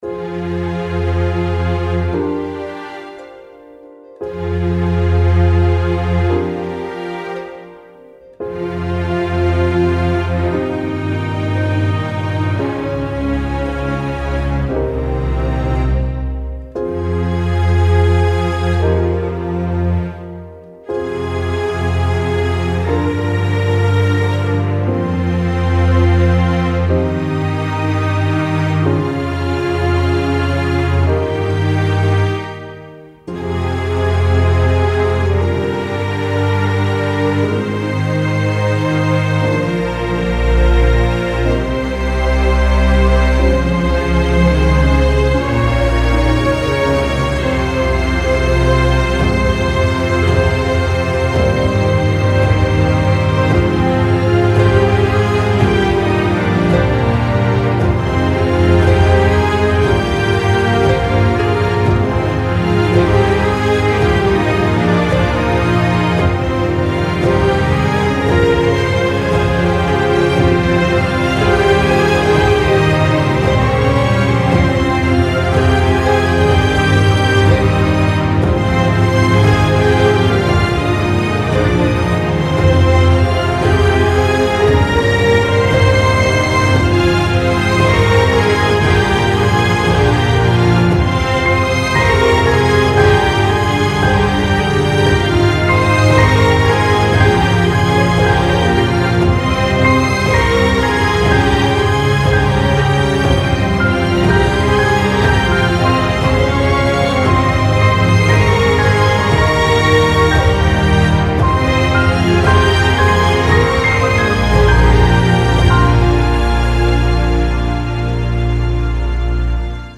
aerien - nostalgique - calme - epique - nappes